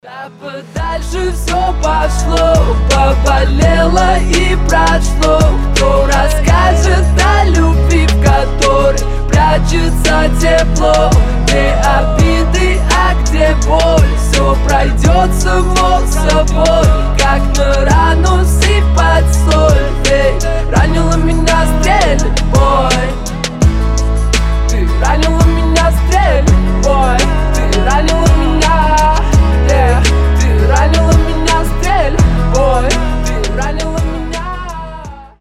• Качество: 320, Stereo
гитара
лирика
красивый мужской голос